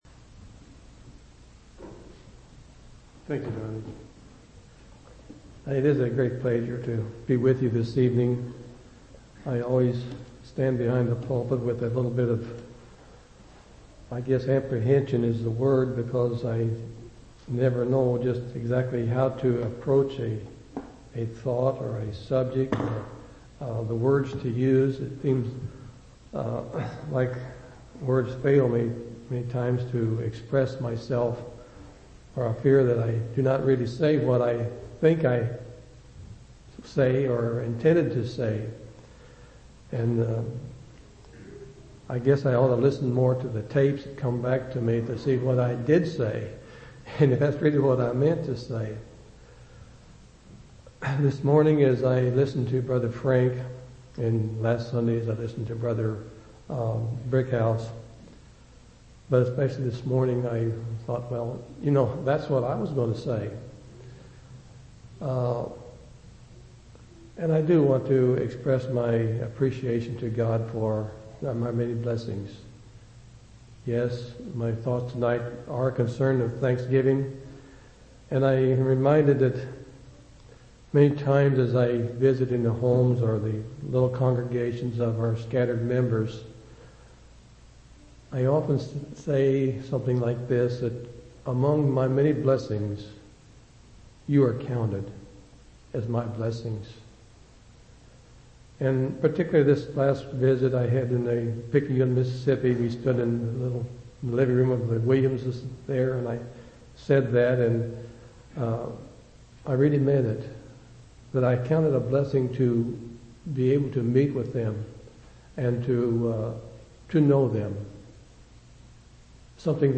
11/23/2003 Location: Temple Lot Local Event